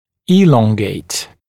[‘iːlɔŋgeɪt][‘и:лонгейт]удлинять, растягивать